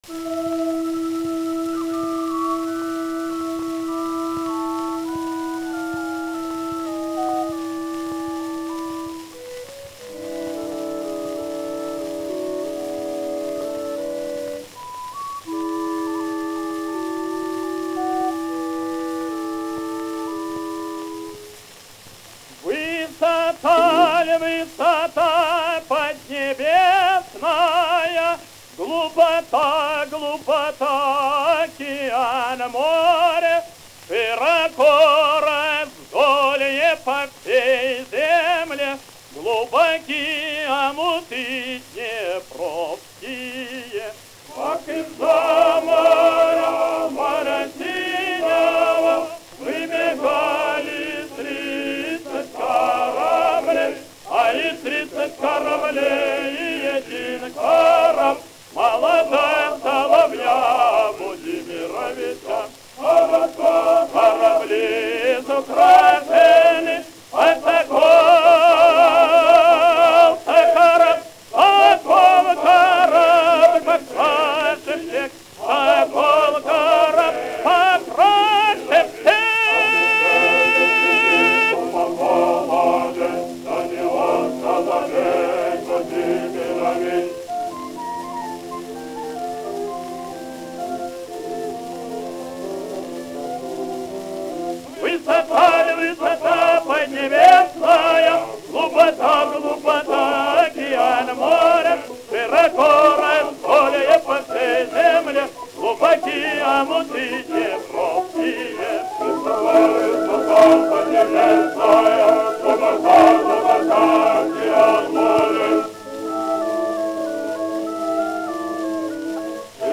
(w. chorus)